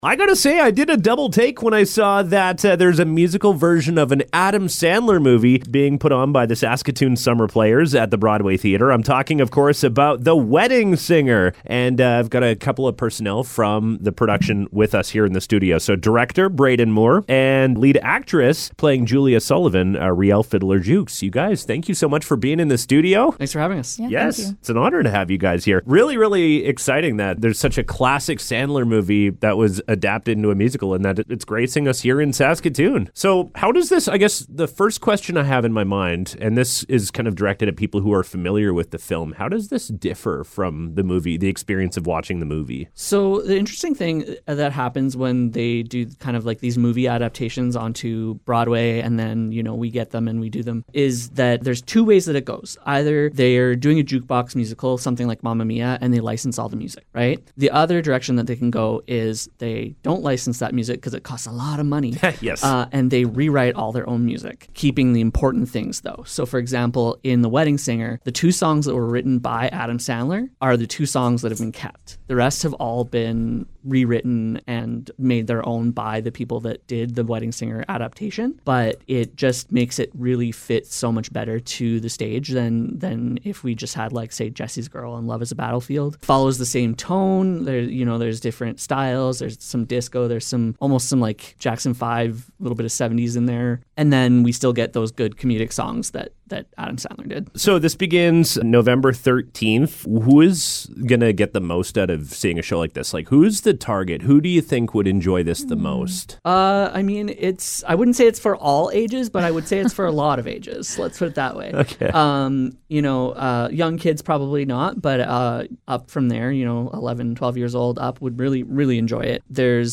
Interview: The Wedding Singer